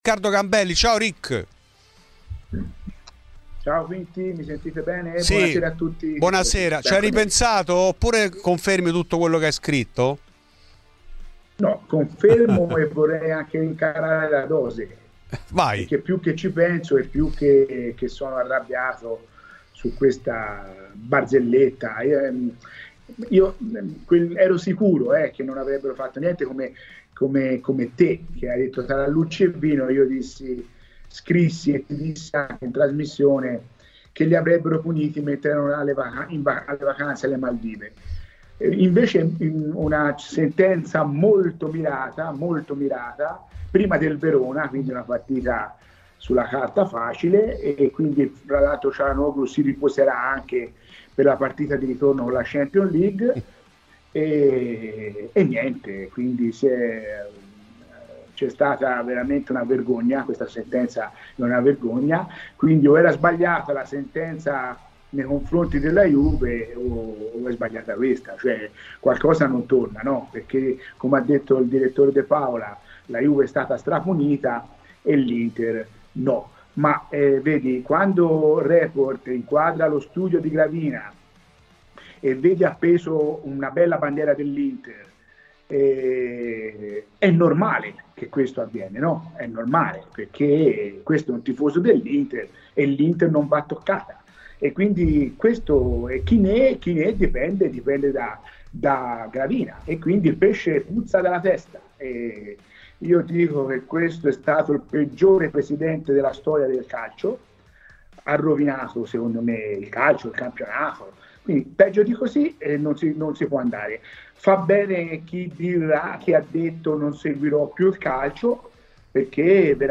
Fuori di Juve , trasmissione di Radio Bianconera